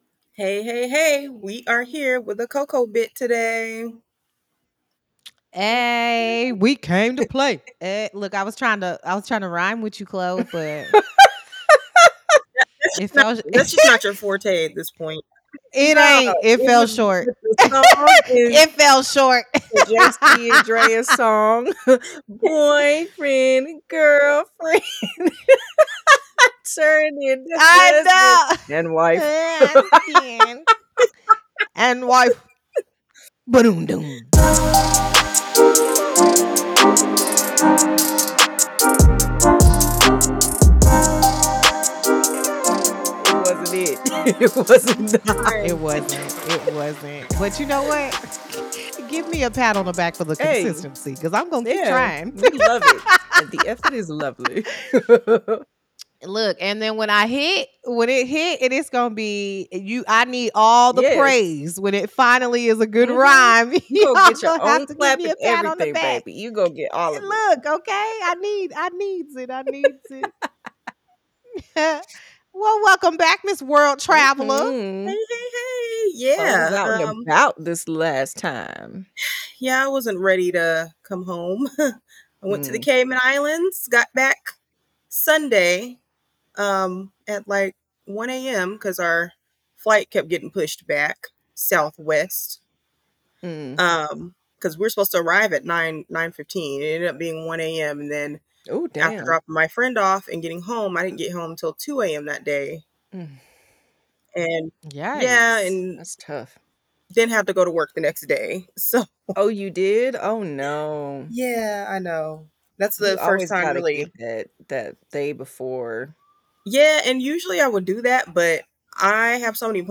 Play Rate Apps Listened List Bookmark Share Get this podcast via API From The Podcast CoCo Conversations What do you get when you let 3 friends of color discuss relationships, mental health, pop culture and everything in between? An honest and random a** conversation!